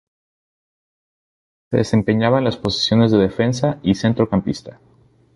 cen‧tro‧cam‧pis‧ta
Pronunciado como (IPA)
/θentɾokamˈpista/